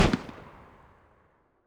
riflerev2.wav